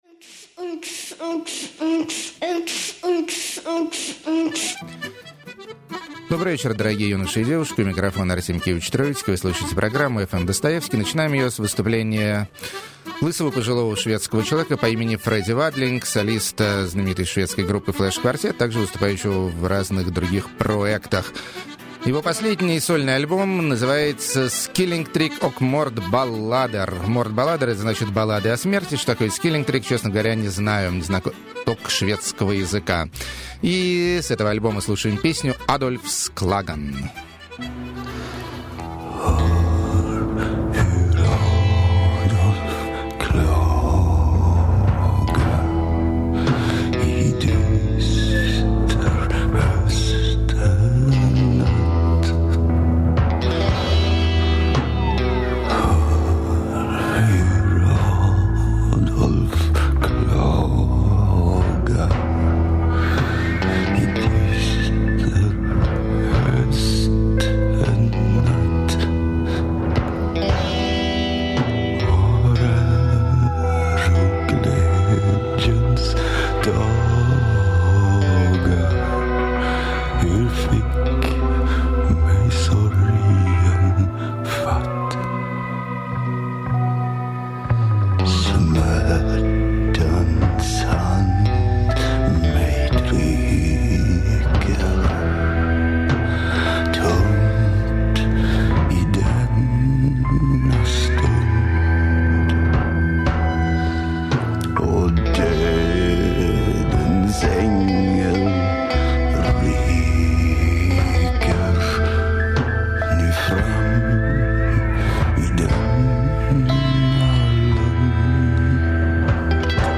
Эротоманская Облегченная Музыка.
Новое Английское Электро.
Индуистские Псалмы.] 10.
Дуэт Умных Аккордеонистов, Добивающихся Душевности.
Эксцентричная Актриса Поет Диковатые Ретро-песенки.
Блюз В Стиле Doom Metal.